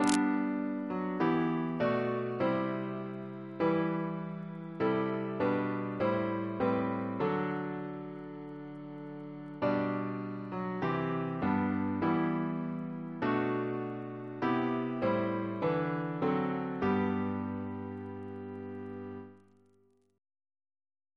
Double chant in G Composer: Chris Biemesderfer (b.1958) Note: for Psalm 19